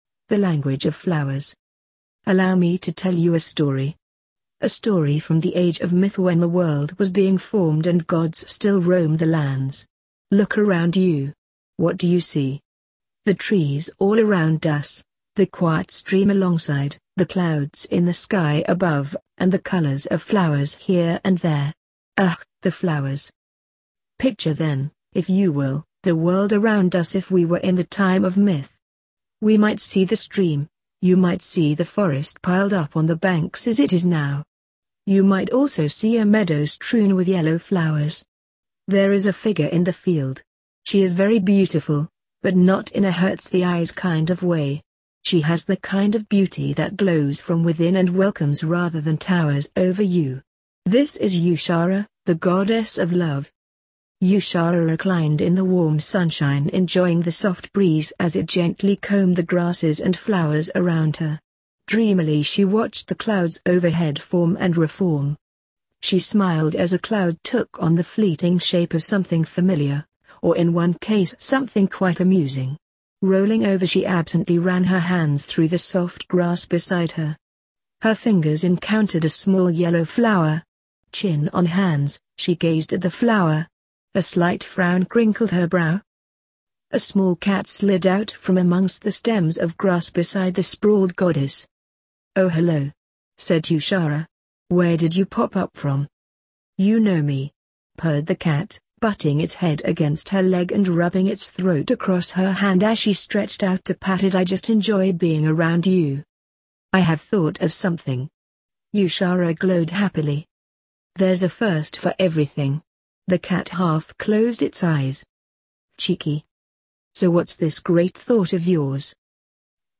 The_Colour_of_Flowers-fromtexttospeech.mp3